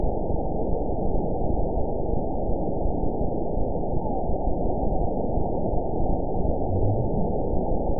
event 922053 date 12/26/24 time 01:41:48 GMT (5 months, 3 weeks ago) score 8.89 location TSS-AB02 detected by nrw target species NRW annotations +NRW Spectrogram: Frequency (kHz) vs. Time (s) audio not available .wav